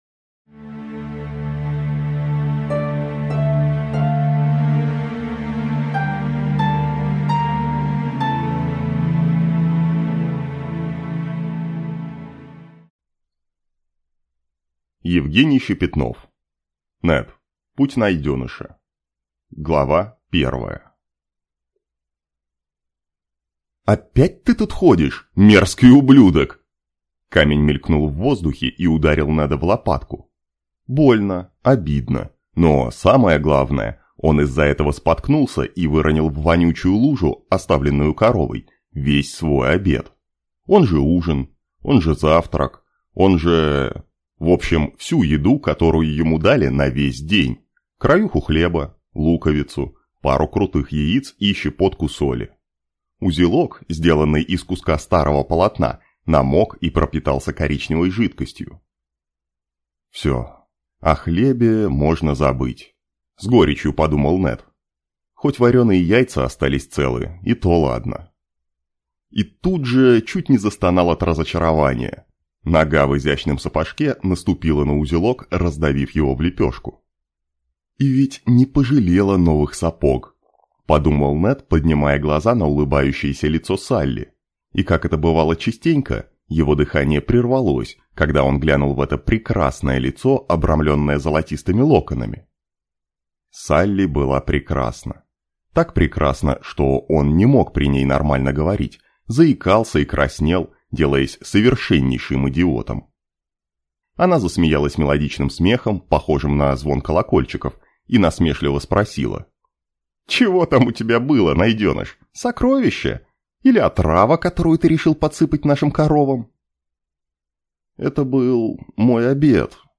Аудиокнига Путь Найденыша | Библиотека аудиокниг
Прослушать и бесплатно скачать фрагмент аудиокниги